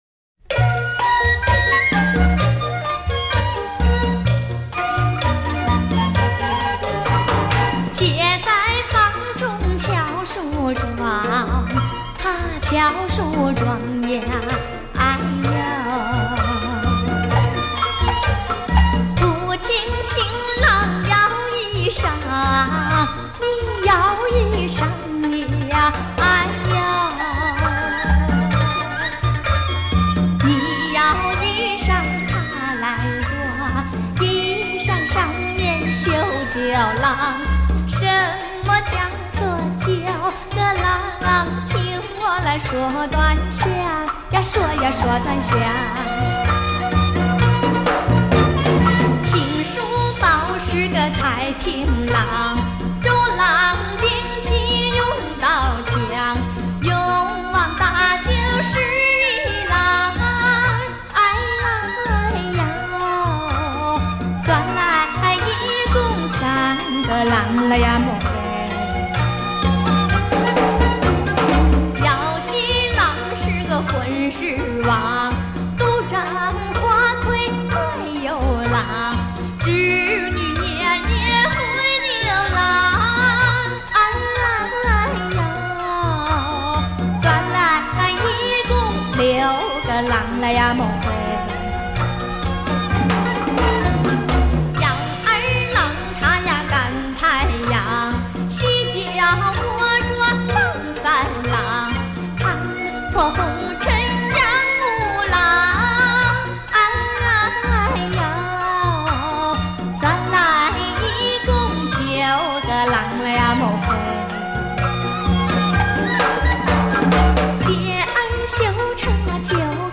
最靓声早期录音瑰宝纯樸美音 原声重现 首次推出SACD